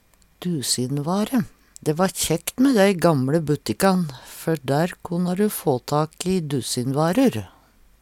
DIALEKTORD